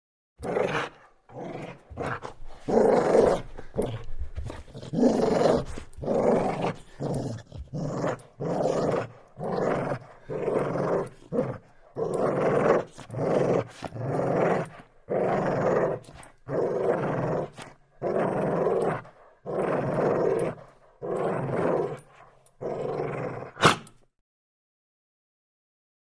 DogGoldenLabGrowls AT021601
Dog, Golden Lab, Growls And Snarls With Light Collar Tag Clinks, Snort At Tail. [close Stereo],